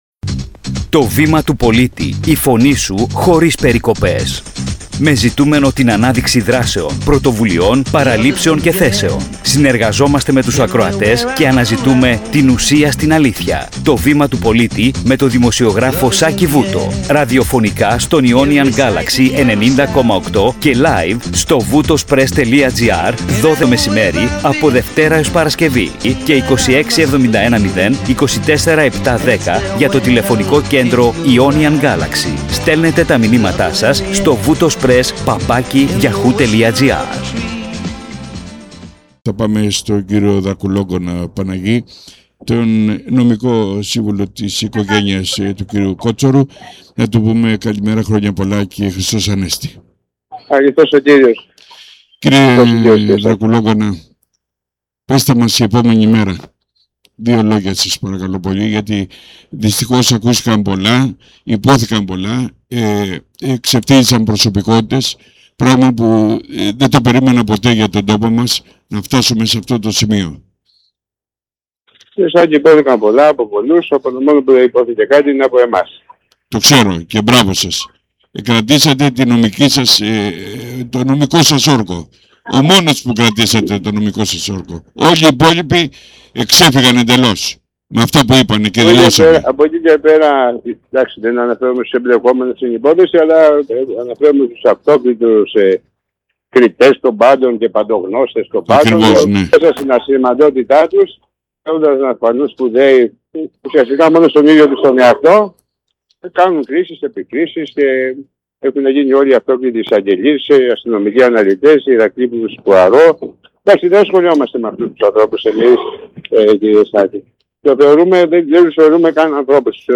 🧾 Περίληψη συνέντευξης
Σοβαρός και φορτισμένος, με έμφαση: